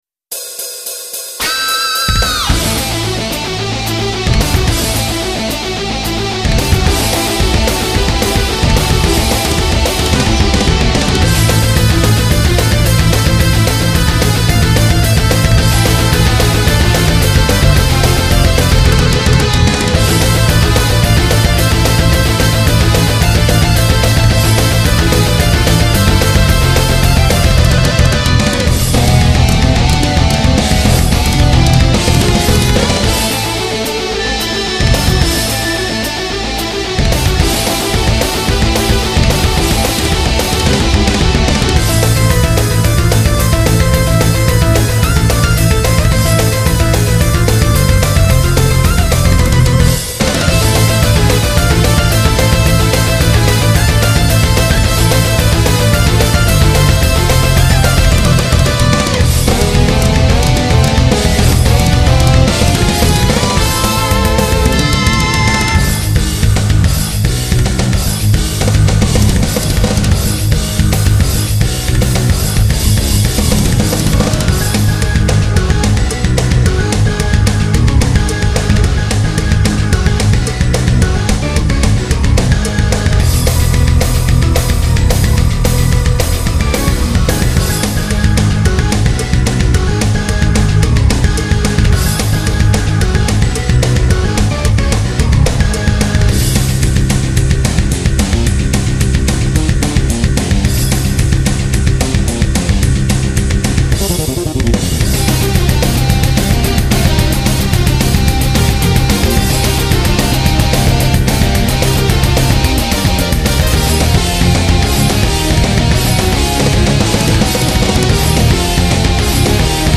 音源モジュール YAMAHA MU2000